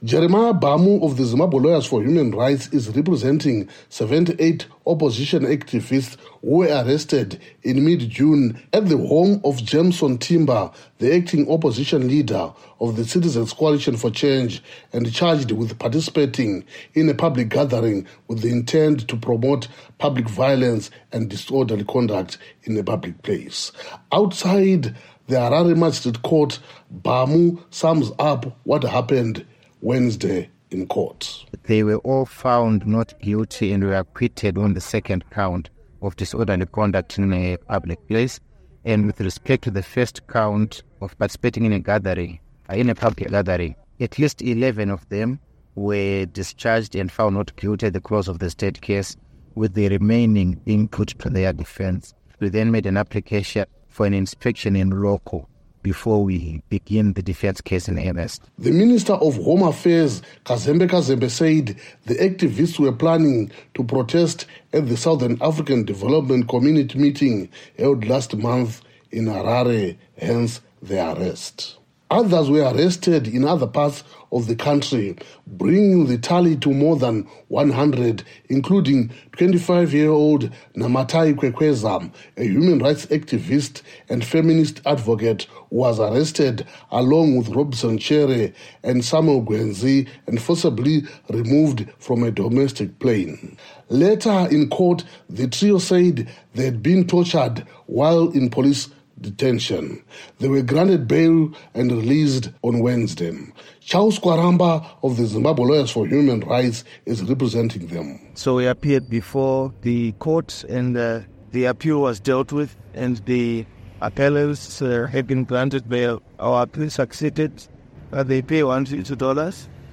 reports for VOA from Harare